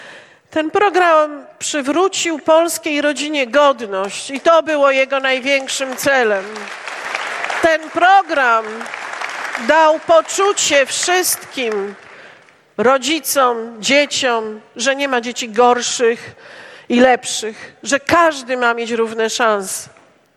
W hali sportowej Szkoły Podstawowej nr 4 w Sandomierzu Beata Szydło mówiła: Jestem jedną z Was, widzę jak to jest ważne, by stać po stronie polskich spraw.